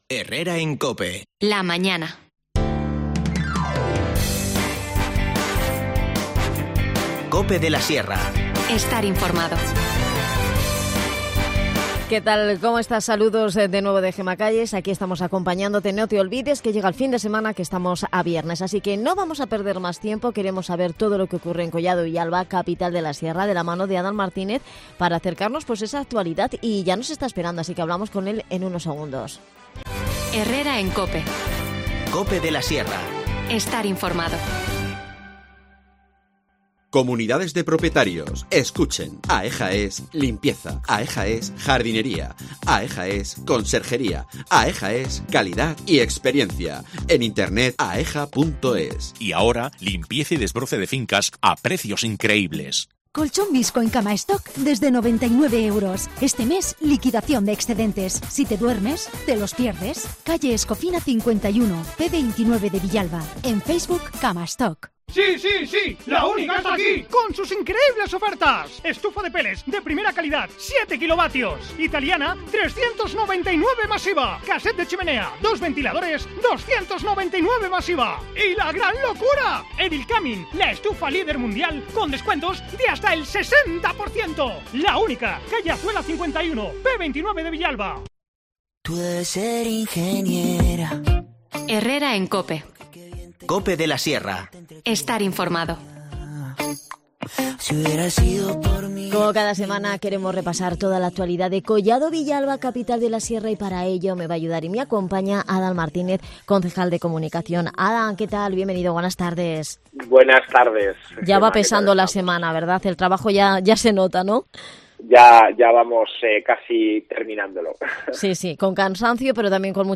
Redacción digital Madrid - Publicado el 14 may 2021, 13:26 - Actualizado 17 mar 2023, 18:53 2 min lectura Descargar Facebook Twitter Whatsapp Telegram Enviar por email Copiar enlace Adan Martínez, concejal de Comunicación en Collado Villalba, repasa la actualidad del municipio en la que nos habla de los pasos que están dando para sancionar a la concesionaria del túnel Batalla de Bailén por incumplimiento de contrato. En el área de formación y empleo nos avanza el nuevo espacio de trabajo colaborativo que han puesto en marcha en la Cantera de Empresas dirigido a emprendedores, autónomos y empresarios.
Las desconexiones locales son espacios de 10 minutos de duración que se emiten en COPE, de lunes a viernes.